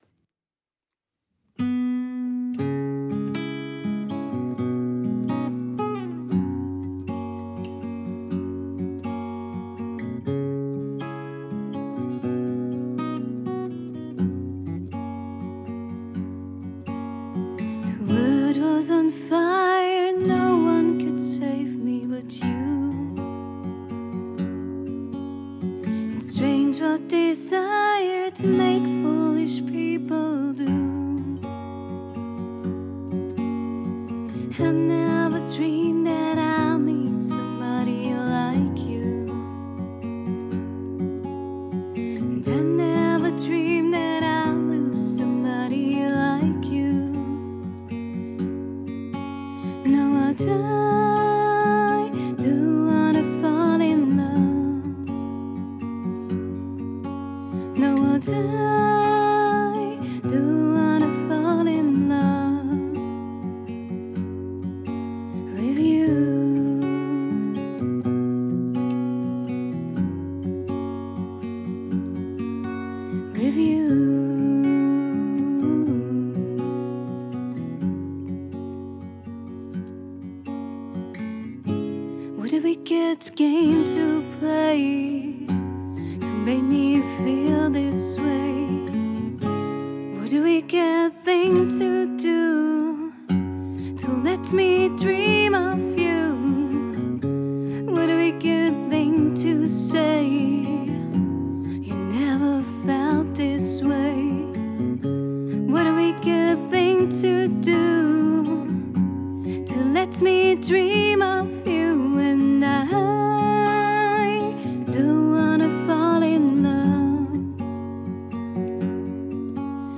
Hochzeits- und Eventsängerin